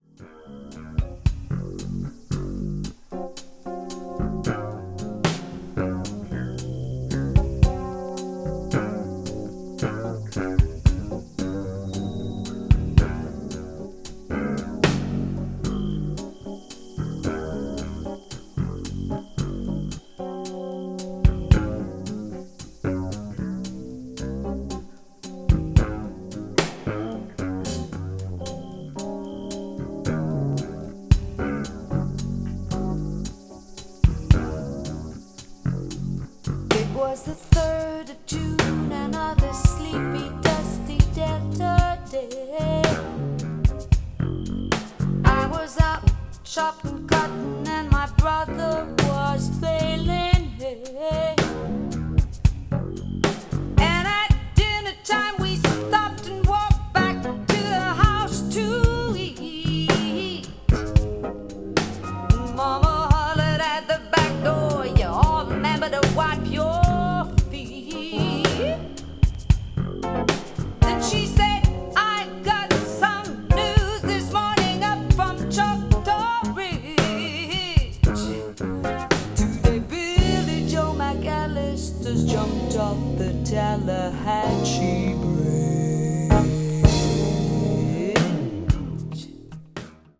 media : EX/EX(わずかにチリノイズが入る箇所あり)
chanson   contemporary jazz   french pop   jazz vocal